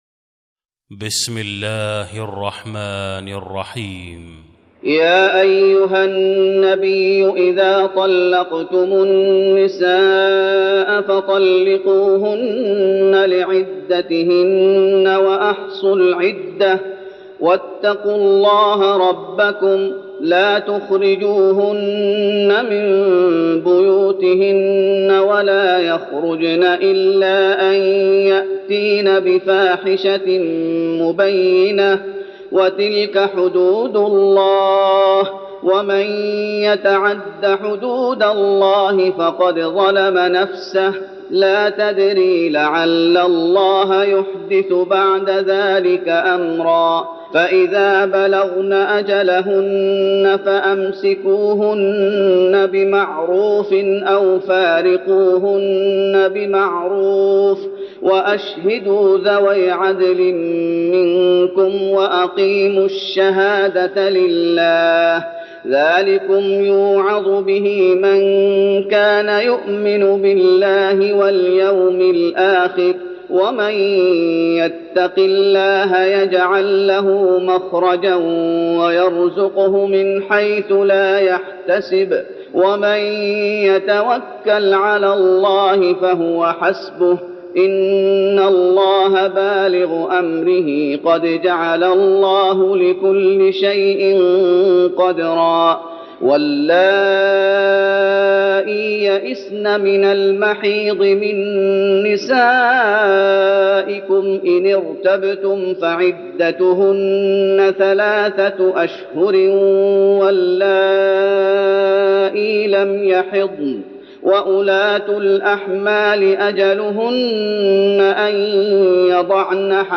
تراويح رمضان 1412هـ من سورة الطلاق Taraweeh Ramadan 1412H from Surah At-Talaq > تراويح الشيخ محمد أيوب بالنبوي 1412 🕌 > التراويح - تلاوات الحرمين